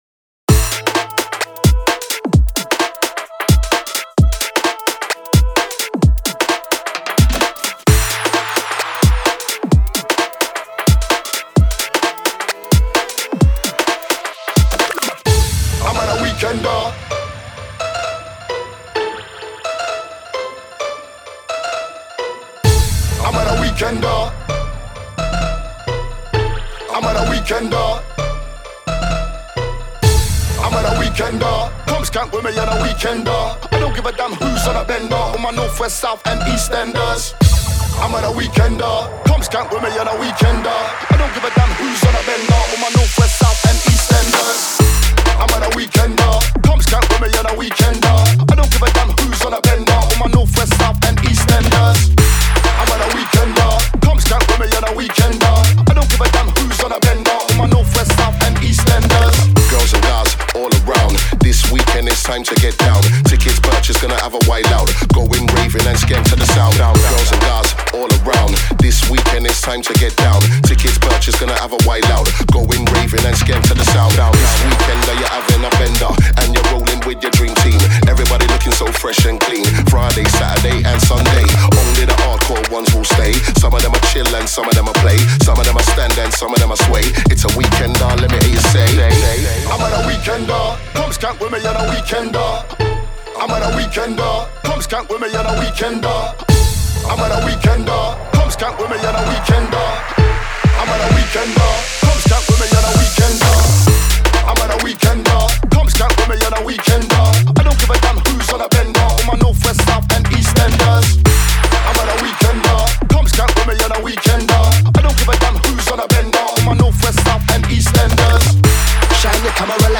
2 Step Radio Edit - 8A - 130